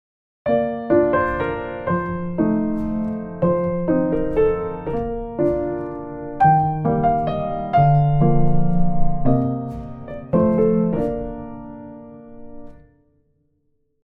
7級B/変ロ長調３拍子
メロディー変奏の例
変奏.mp3